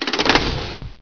chungusLever.ogg